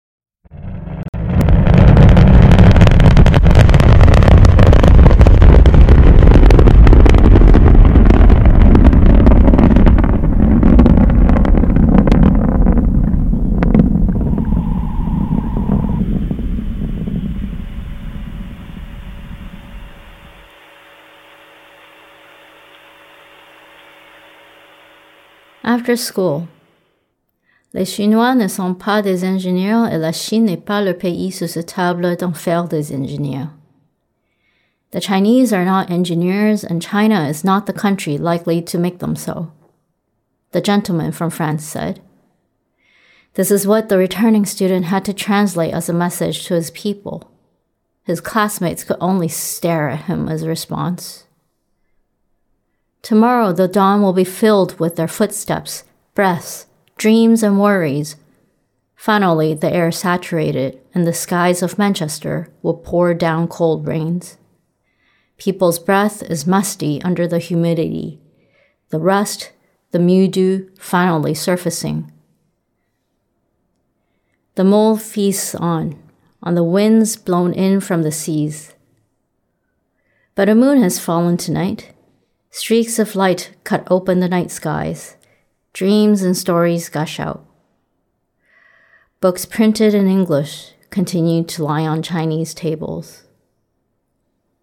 Listen to the soundscape & poem.